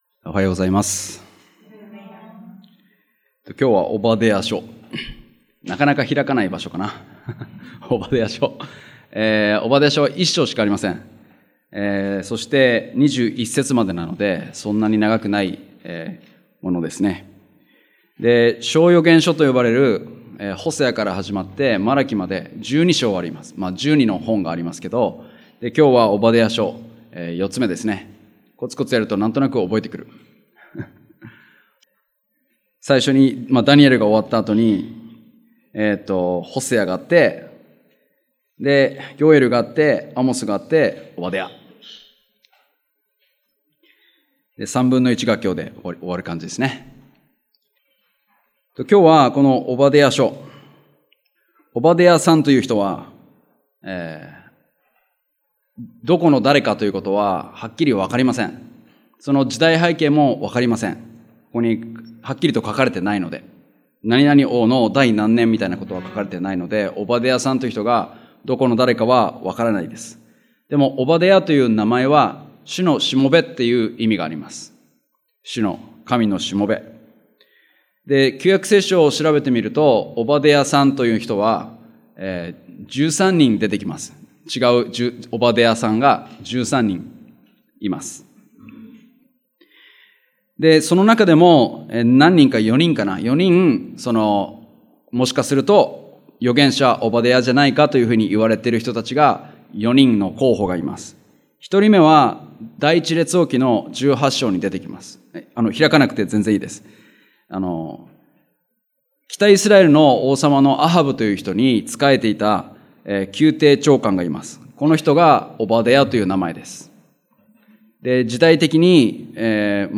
日曜礼拝：オバデヤ書
礼拝メッセージ